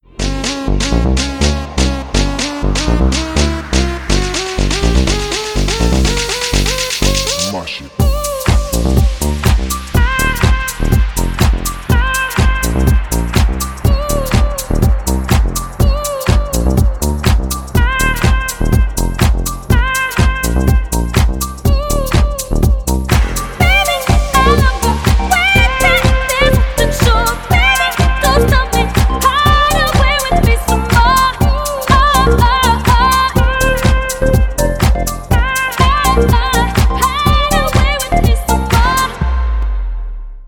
• Качество: 320, Stereo
dance
club
Bass